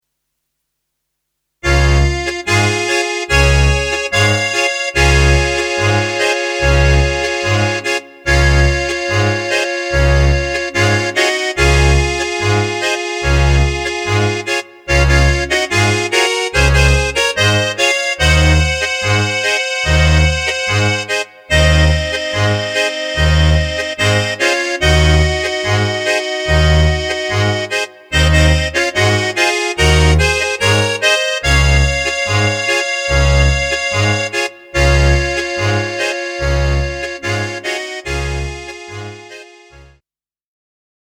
Db-Dur